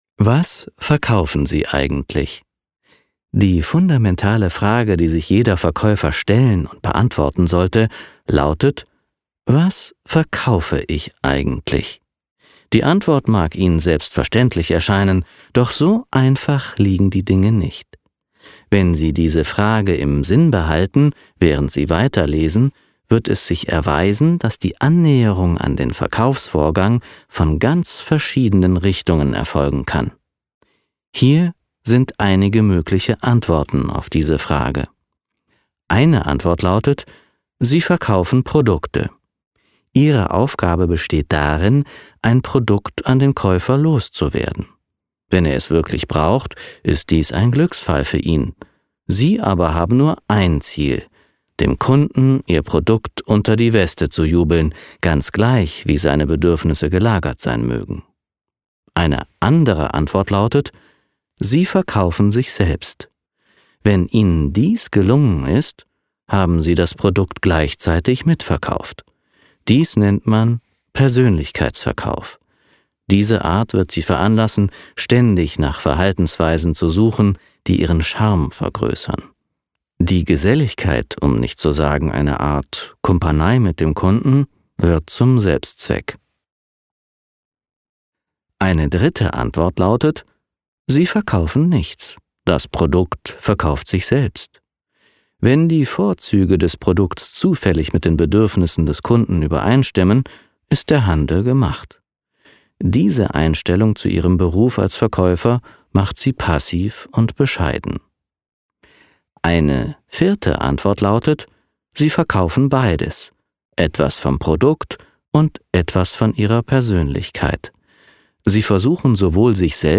Hörbuch Sachbuch Schlaf – Auszug